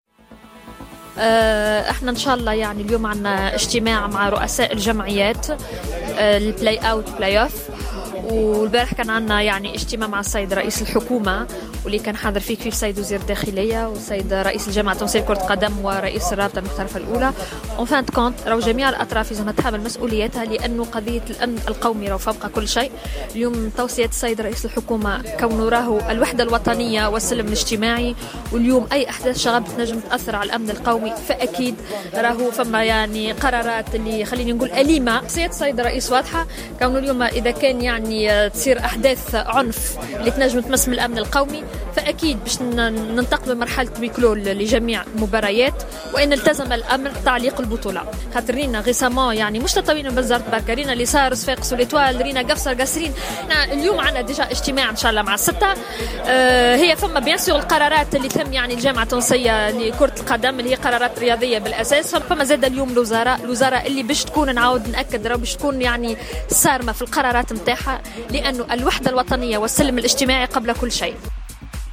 اكدت وزيرة شؤون الشباب و الرياضة ماجدولين الشارني في تصريح خاص بجوهرة اف ام انه خلال الاجتماع امس مع رئيس الحكومة يوسف الشاهد و رئيس الجامعة التونسية لكرة القدم وديع الجريء و وزير الداخلية الهادي مجدوب تم التطرق الى تزايد اعمال العنف و الشغب في الملاعب الرياضية .
ماجدولين الشارني: وزيرة شؤون الشباب و الرياضة